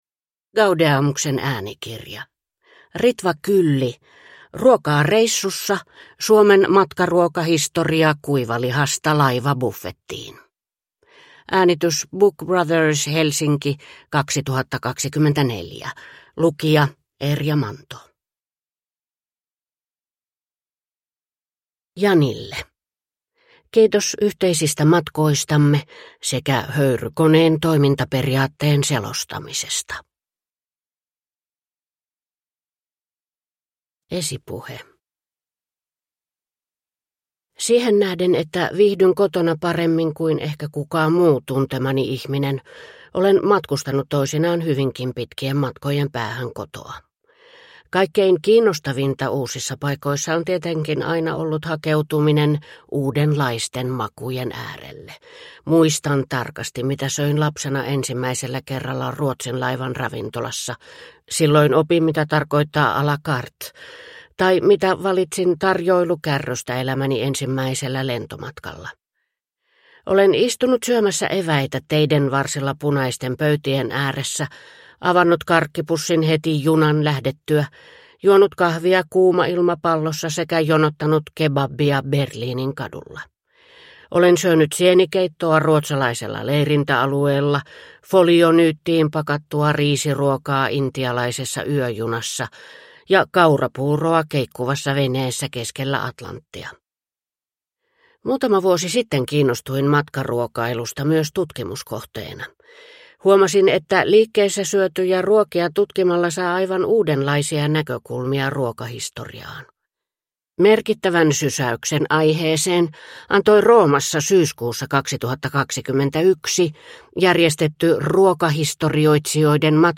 Ruokaa reissussa / Ljudbok